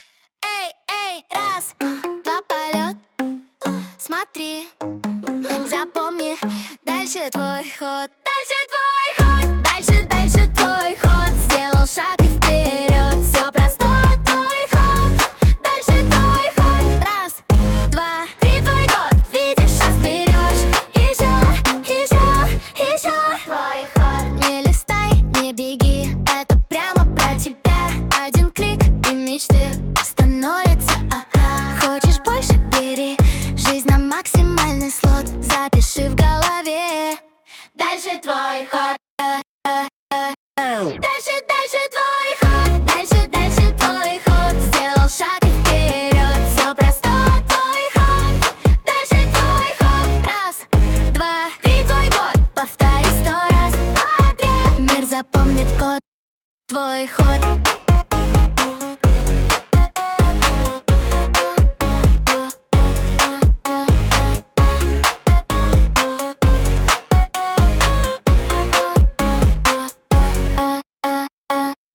• Быстрые джинглы
Suno позволяет создавать динамичные, ритмичные треки, заточенные под короткий формат и первые секунды просмотра.